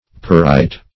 perite - definition of perite - synonyms, pronunciation, spelling from Free Dictionary Search Result for " perite" : The Collaborative International Dictionary of English v.0.48: Perite \Pe*rite"\, a. [L. peritus.]